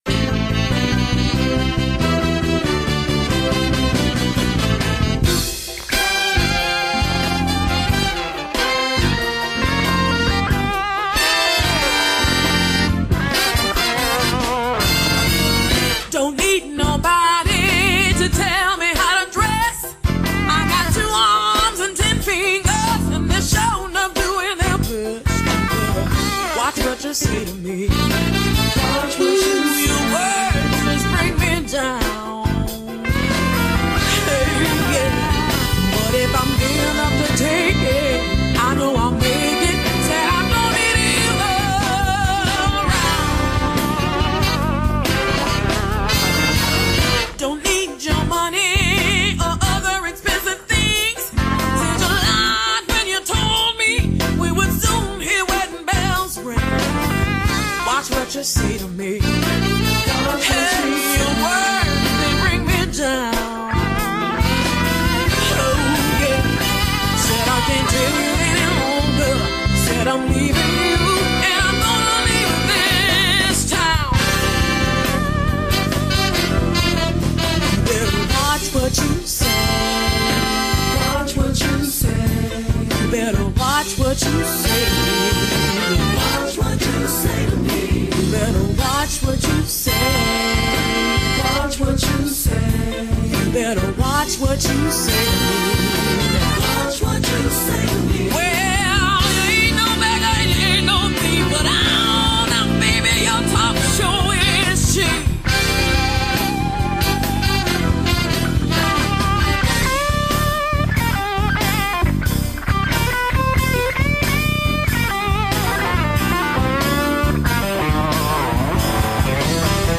Descarga directa Prepárate para disfrutar con buen Blues, en bittorrent, UPVRadio y esta misma web. Porque hoy te traemos el especial BluesBaltica & Bluesfest Eutin, el mayor festival de Blues de Alemania y gratuito, que tendrá lugar del 15 al 18 de mayo.